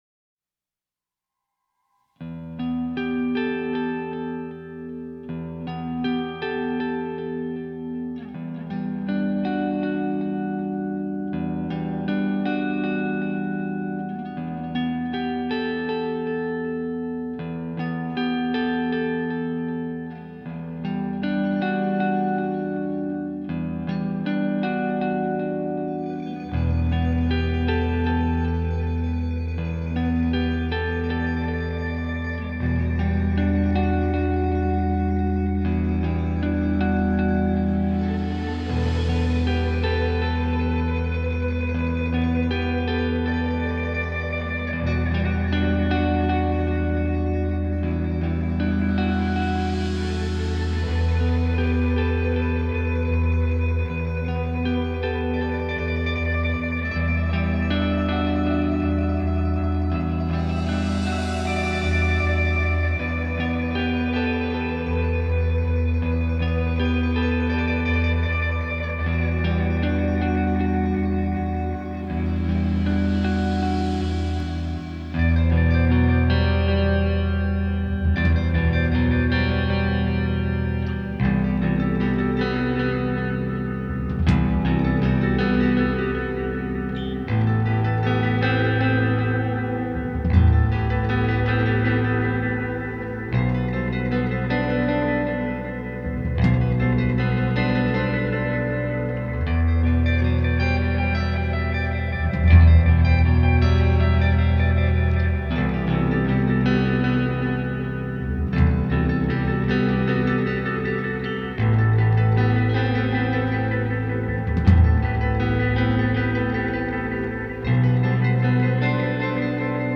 a post-rock band based on San Jose
The new album is full of emotion and energy. song list: